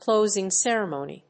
音節clósing cèremony